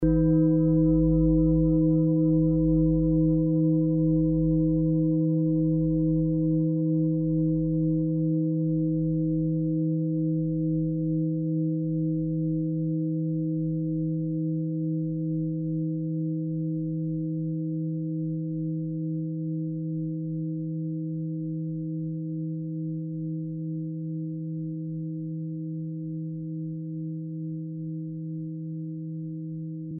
Fuß-Klangschale Nr.2
Sie ist neu und wurde gezielt nach altem 7-Metalle-Rezept von Hand gezogen und gehämmert.
(Ermittelt mit dem Gummischlegel)
fuss-klangschale-2.mp3